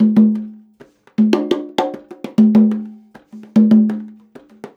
100 CONGAS16.wav